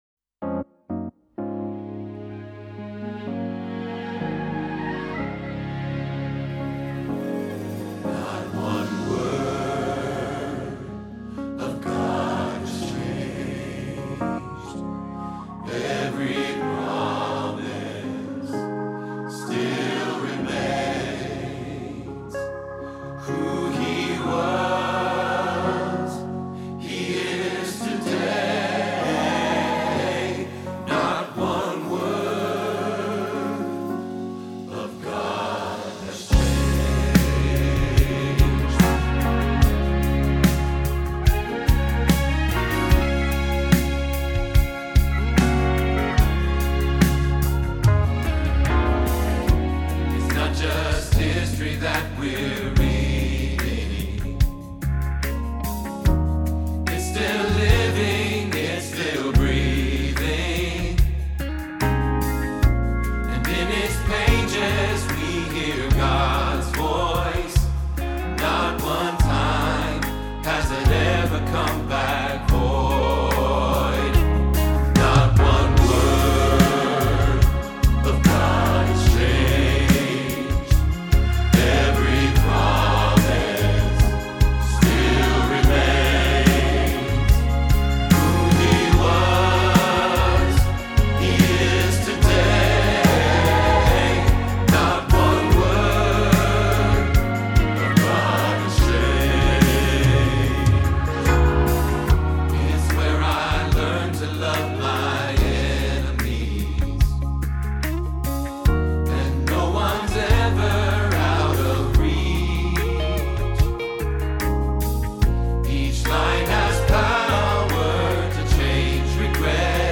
Not One Word – Bass – Hilltop Choir
Not-One-Word-bass.mp3